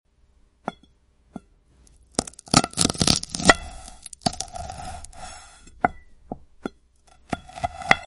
Crystal Tree Glass Cutting ASMR sound effects free download